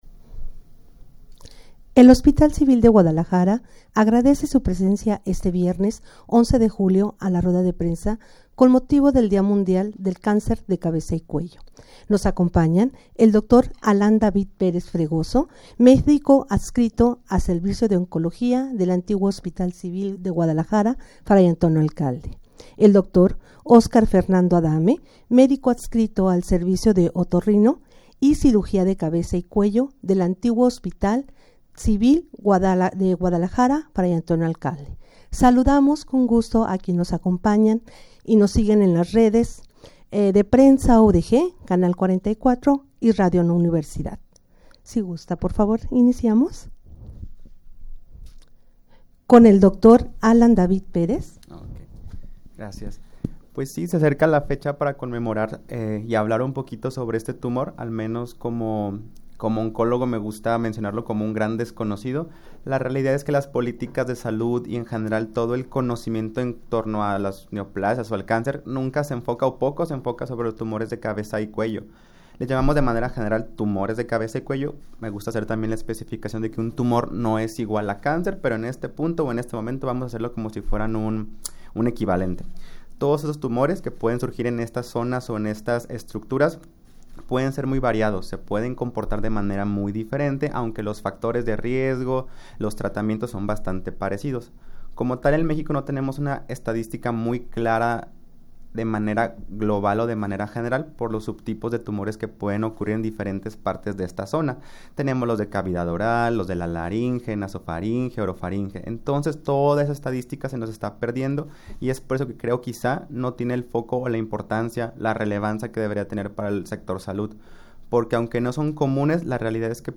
Audio de la Rueda de Prensa
rueda-de-prensa-con-motivo-del-dia-mundial-del-cancer-de-cabeza-y-cuello.mp3